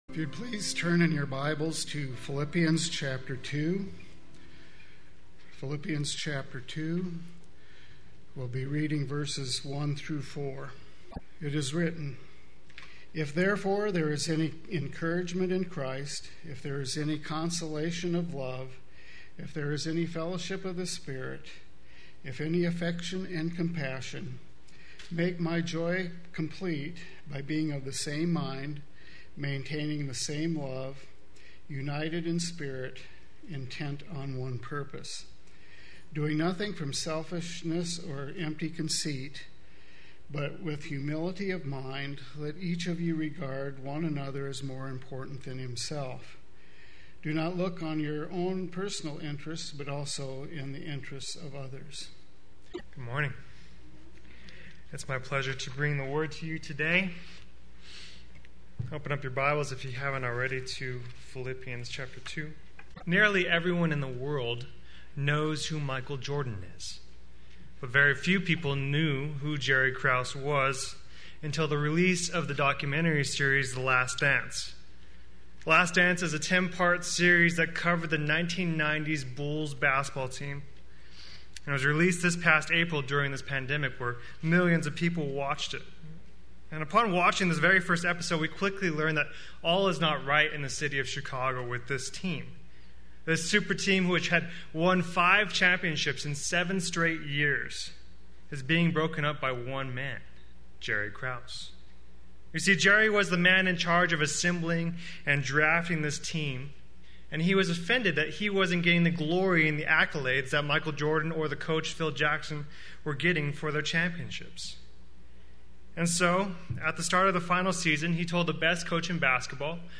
Play Sermon Get HCF Teaching Automatically.
United With One Another Sunday Worship